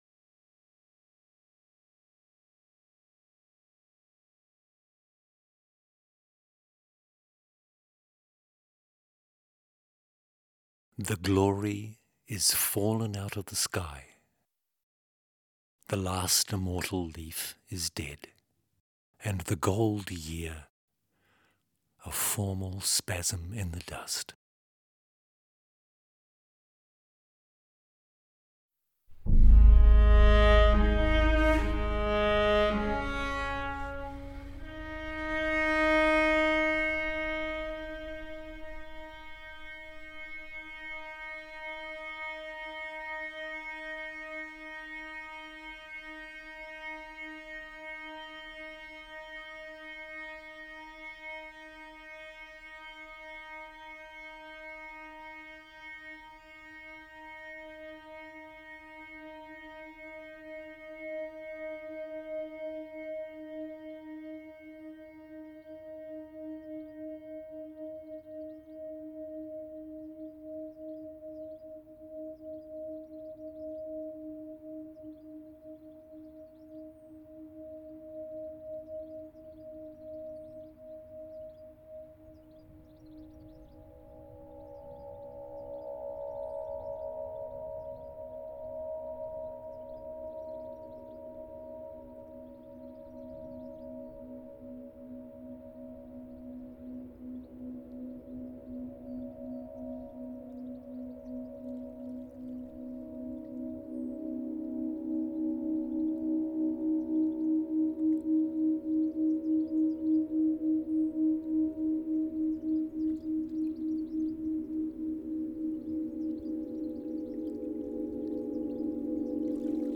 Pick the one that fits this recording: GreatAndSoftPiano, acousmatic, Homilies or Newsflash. acousmatic